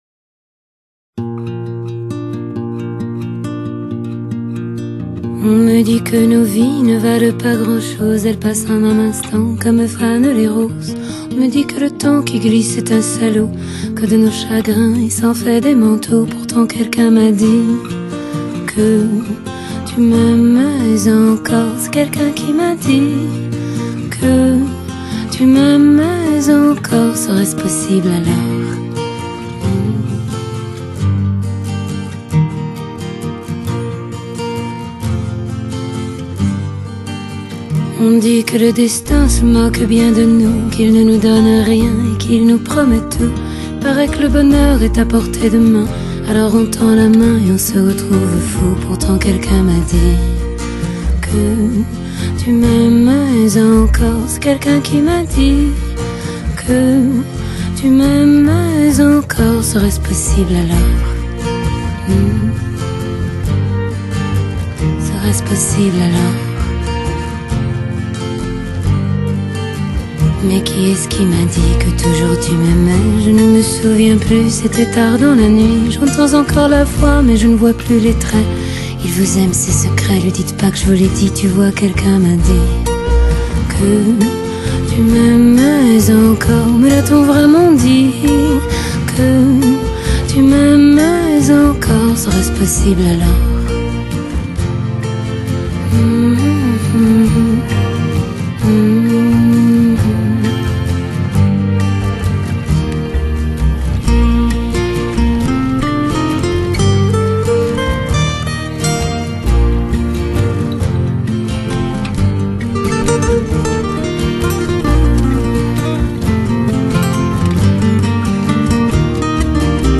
Pop, Songwriter